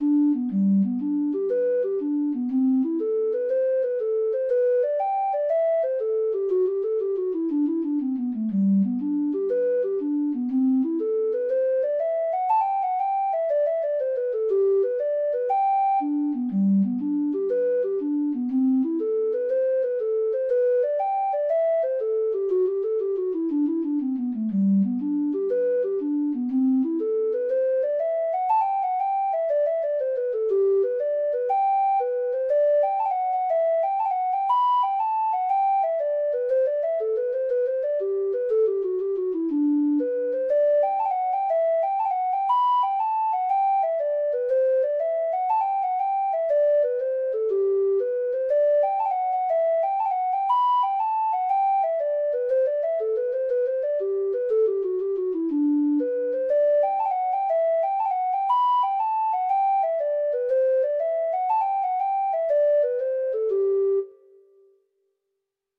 Traditional Music of unknown author.
Irish